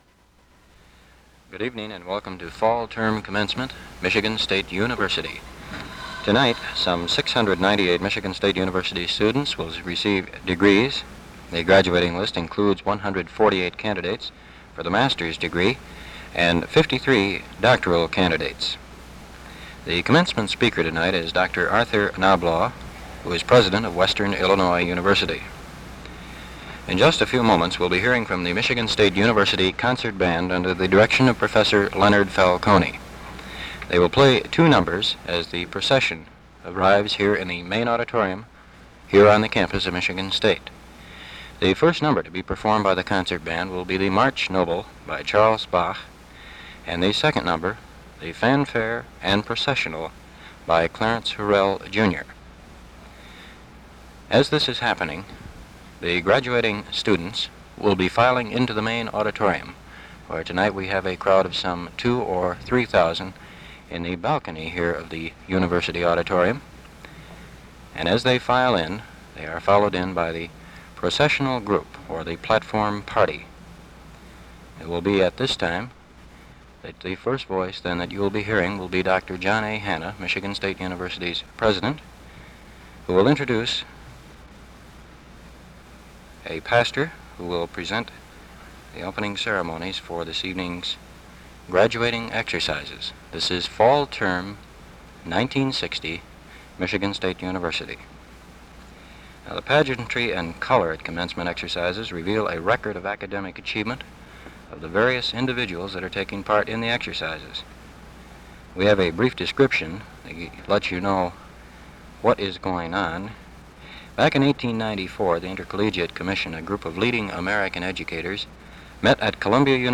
Commencement Address: Fall 1960
Arthur Lewis Knoblauch, President of Western Illinois University, gives the address speech at MSU's Fall 1960 commencement in the University Auditorium.